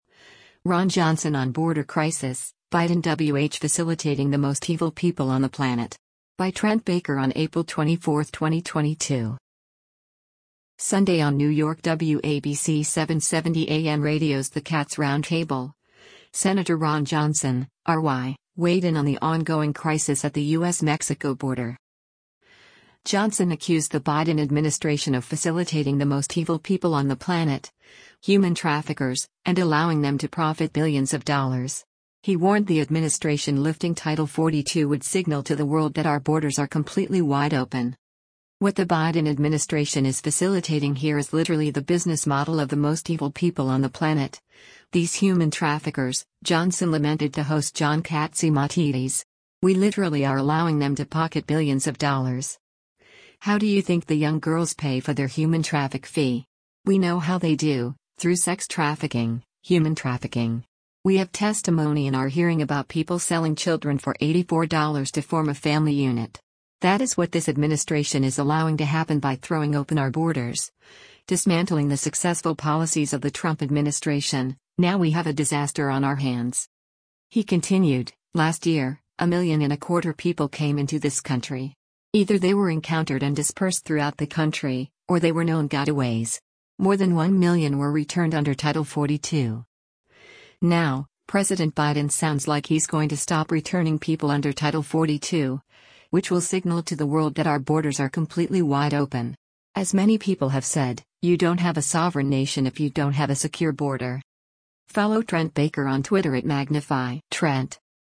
Sunday on New York WABC 770 AM radio’s “The Cats Roundtable,” Sen. Ron Johnson (R-WI) weighed in on the ongoing crisis at the U.S.-Mexico border.